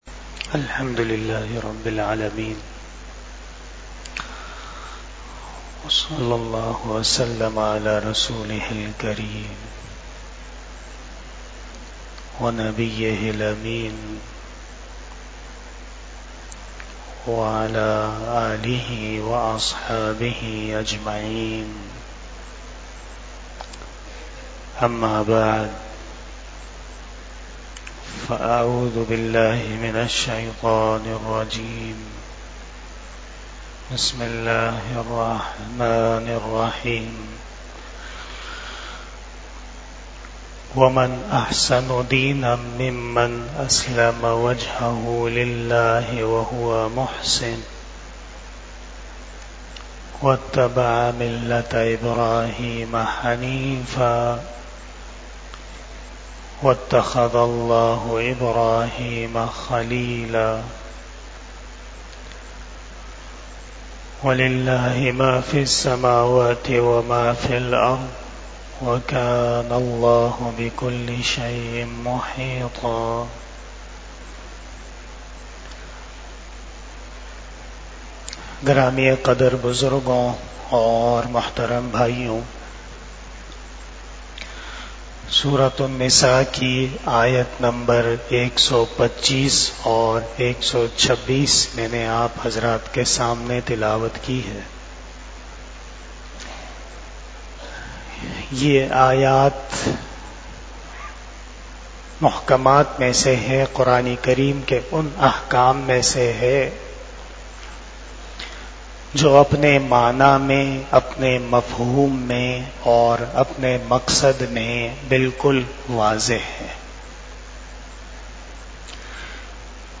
34 Shab E Jummah Bayan 10 October 2024 (07 Rabi ul Sani 1446 HJ)